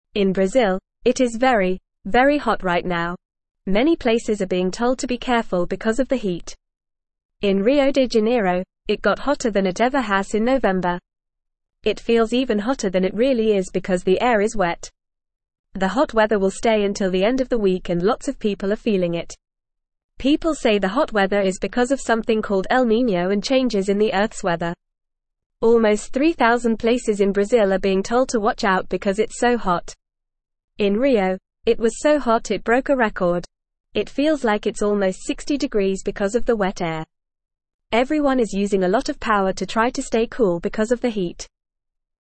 Fast
English-Newsroom-Beginner-FAST-Reading-Hot-Weather-Alert-Brazil-Experiencing-Record-Breaking-Heat.mp3